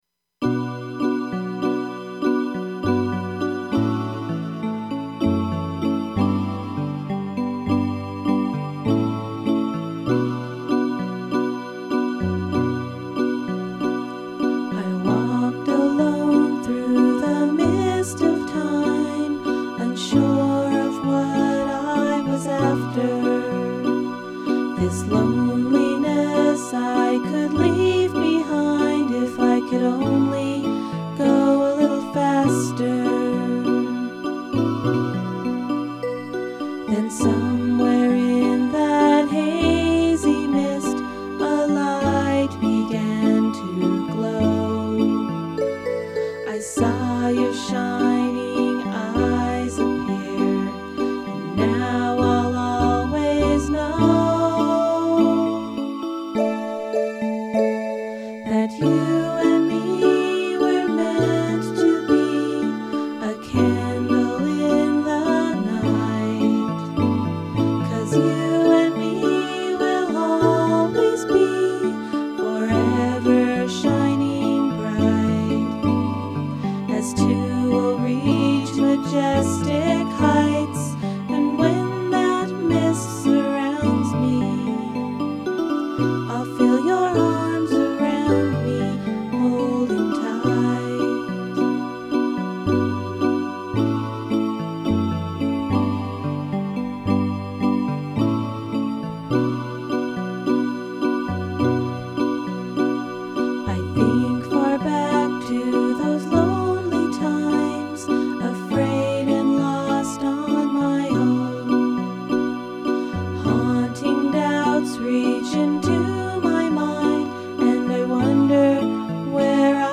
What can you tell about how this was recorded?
This song was written for my assignment for this class. It was sung at an end of term recital.